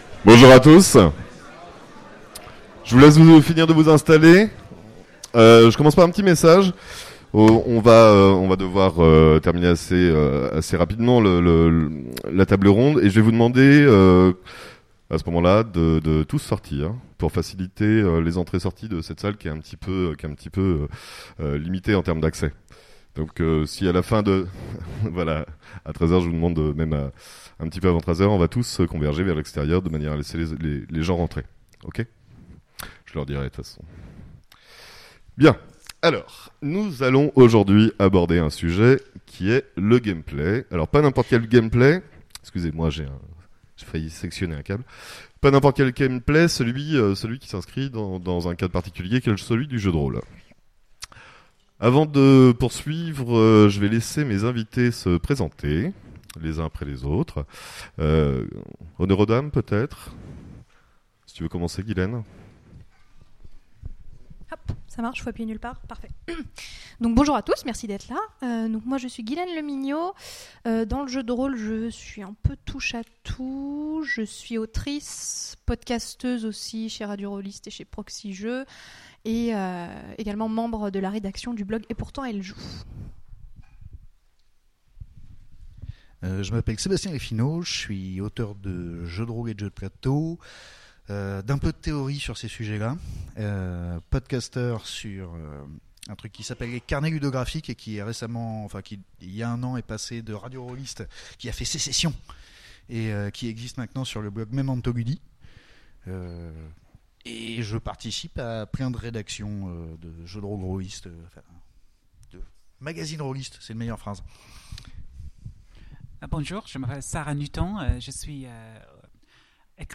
Utopiales 2017 : Conférence Le Gameplay, mécanismes ludiques